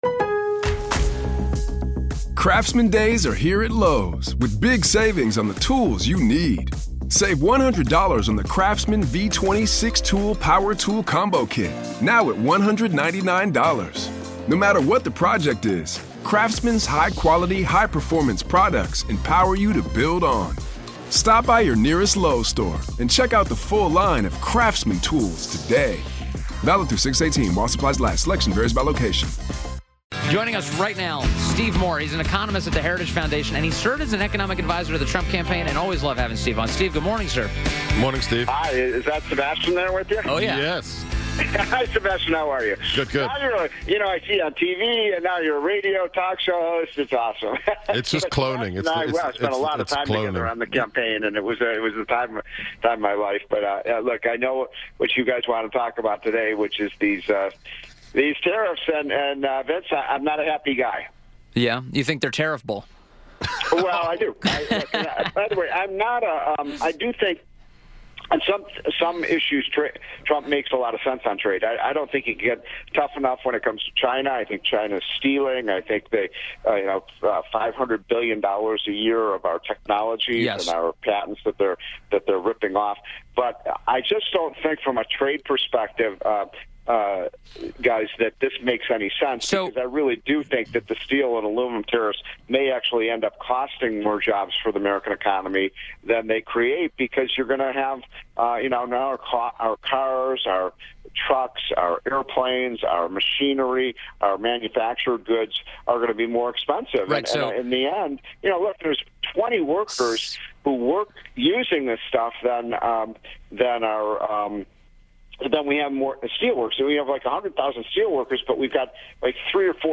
WMAL Interview - STEVE MOORE - 03.02.18